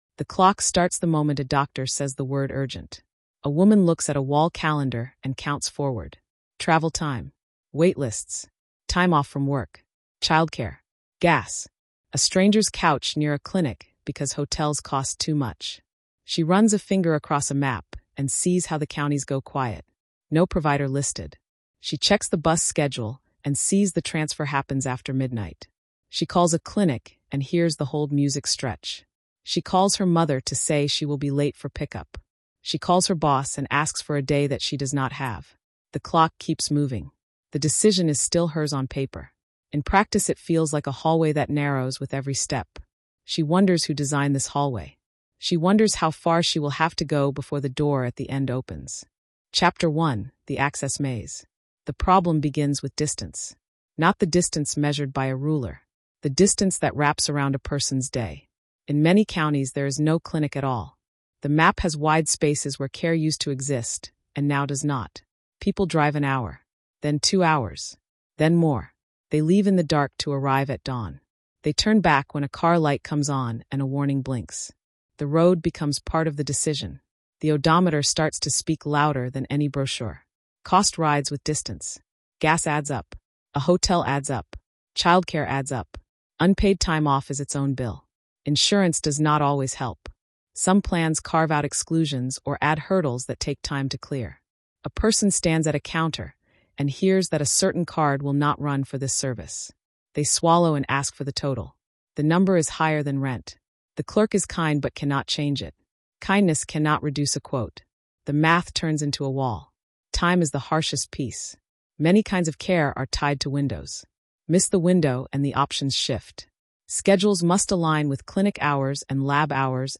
Choice Under Pressure: Barriers to Reproductive Healthcare and Autonomy is a three-chapter investigative documentary told in clear, human terms. It follows one simple question: how real is choice when time, distance, and systems stand in the way.